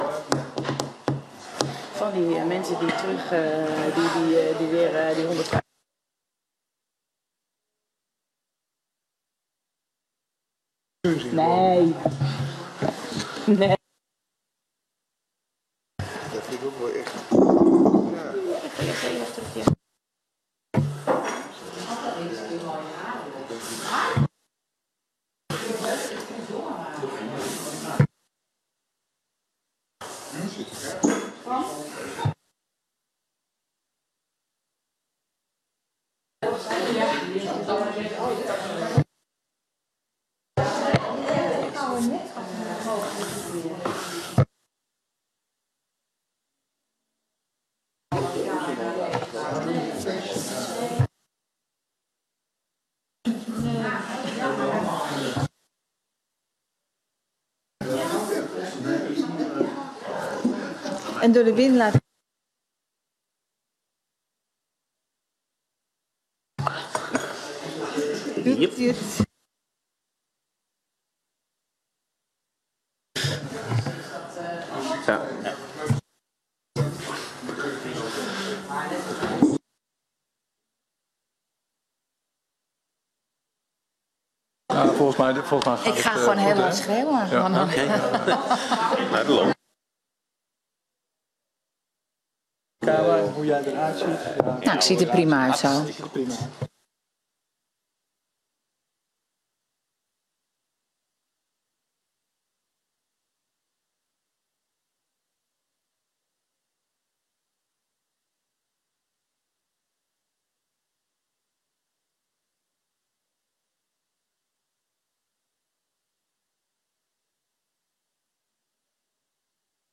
Download de volledige audio van deze vergadering
Locatie: Razende Bol